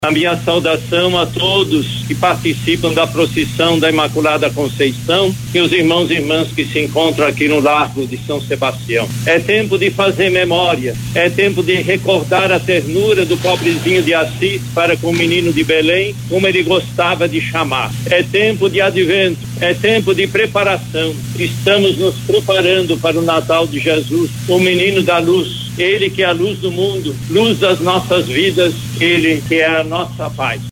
Mais adiante, em frente ao Teatro Amazonas, os fiéis presenciaram a bênção do presépio montado no local.
SONORA-2-DOM-LEONARDO-.mp3